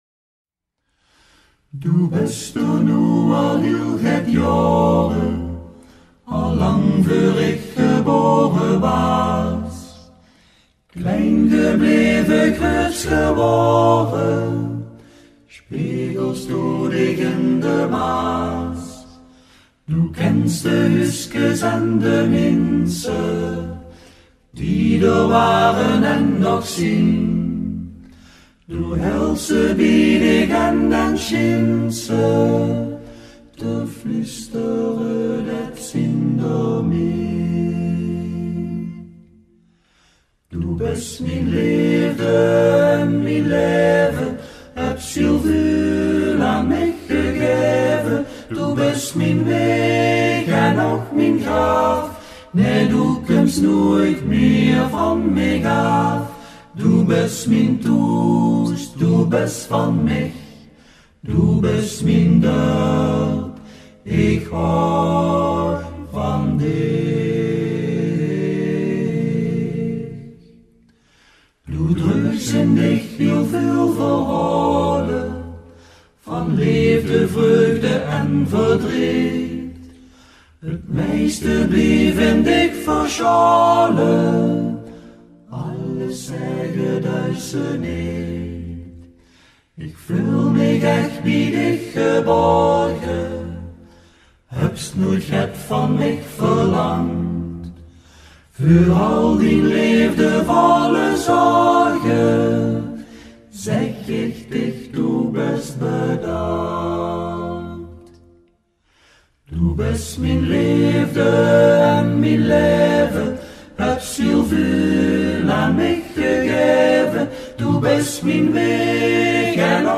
Het volkslied is prachtig gezongen door de Kesselse a capelle groep ‘Mannewerk’